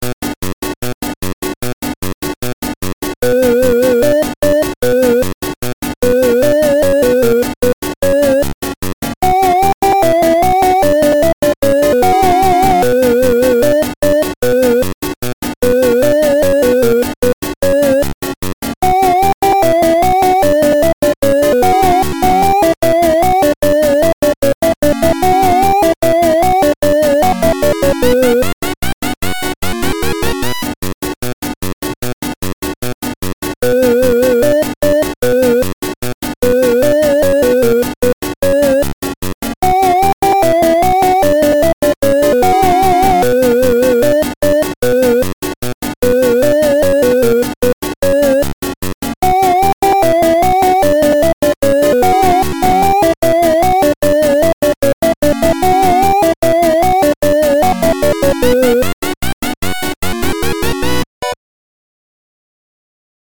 Video Game
Klezmer-esque chiptune melody.